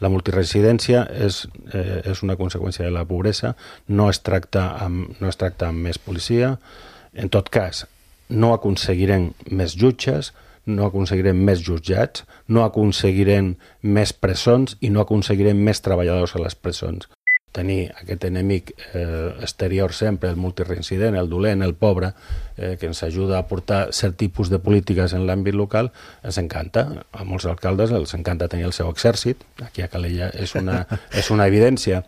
Així ho ha assegurat el portaveu del partit, Sebastian Tejada, a l’espai de l’entrevista política de Ràdio Calella TV d’aquesta setmana, on també ha repassat altres temes d’actualitat com el projecte del futur aparcament de Can Saleta, àmbit en el qual insisteix a reclamar una solució alternativa.